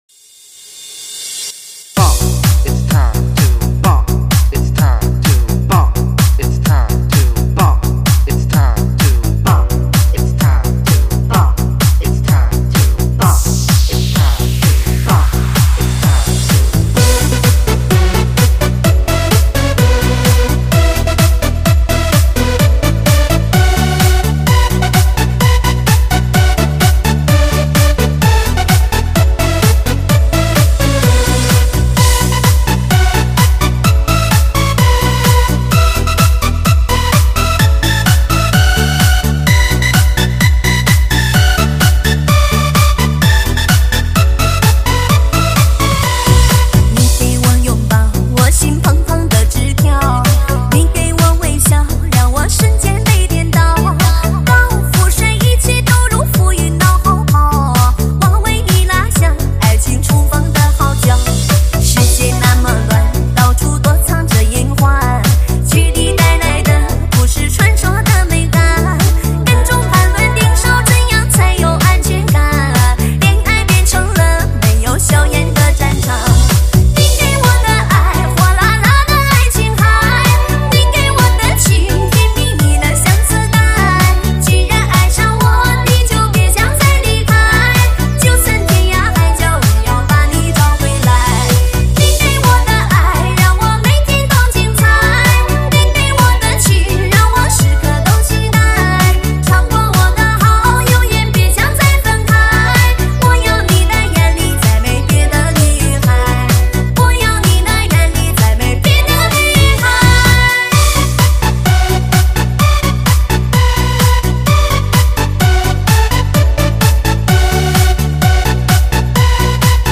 情感时尚诱惑　激情舞动迪吧夜场的新动力